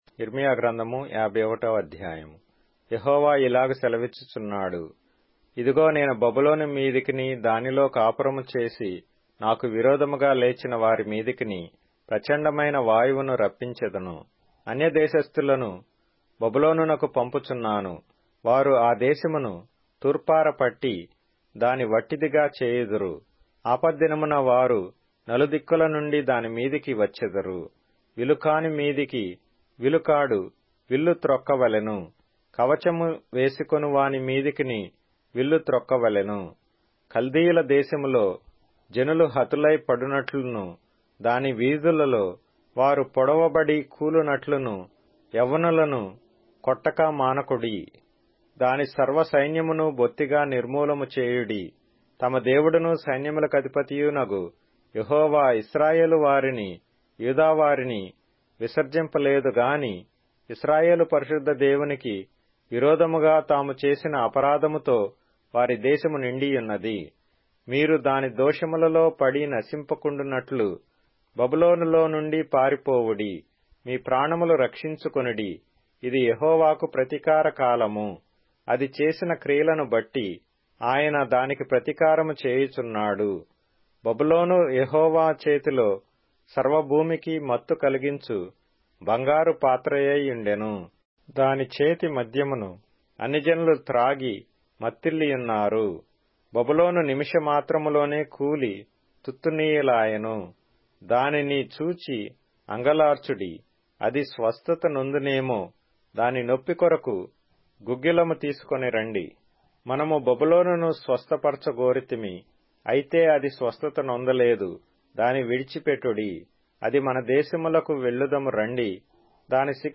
Telugu Audio Bible - Jeremiah 7 in Irvas bible version